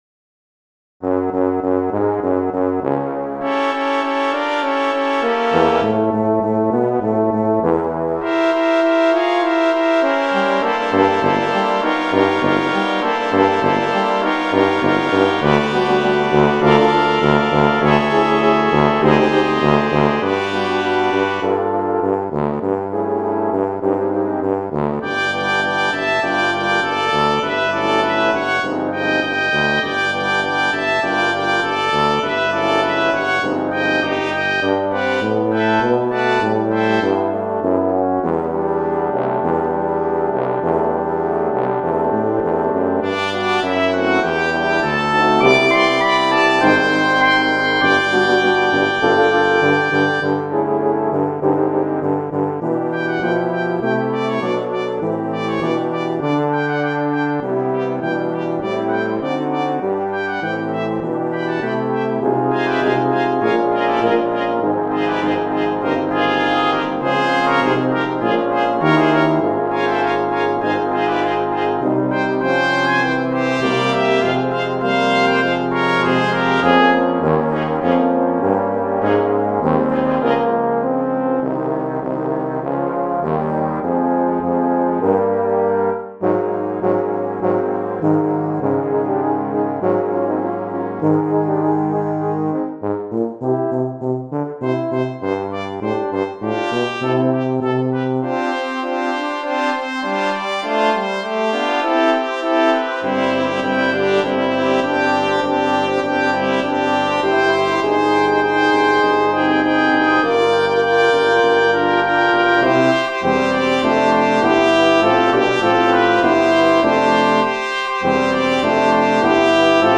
Voicing: Double Brass Quintet